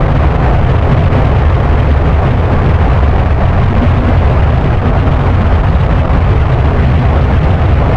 De-harshed thruster audio
I think it sounds good in isolation, but in-game it feels muffled and distant, but not in a way the hull would muffle it.
In my edit of the sound I completely cut the highs around 5kHz and the mid frequencies are boosted.